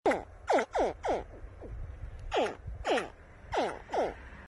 Download Crocodile sound effect for free.
Crocodile